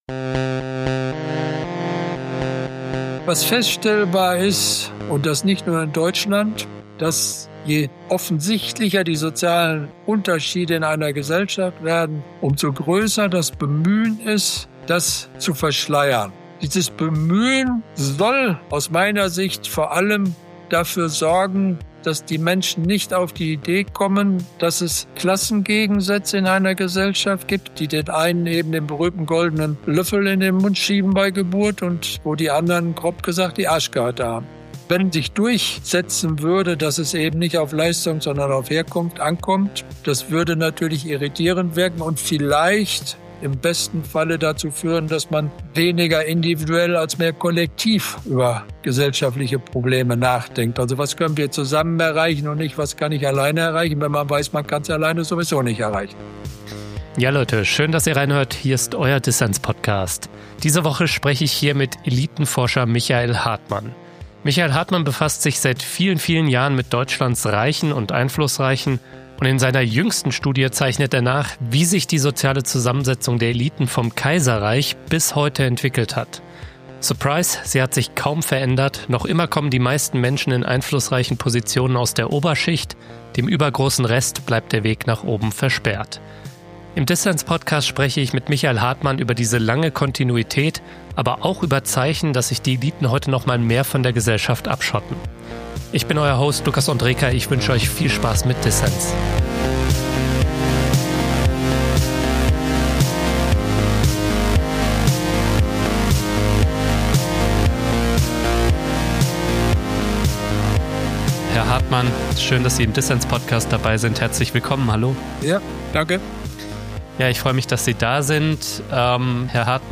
Ein Gespräch über abgeschlossene Zirkel der Macht, Politik von Reichen für Reiche und die Auflösung von Eliten.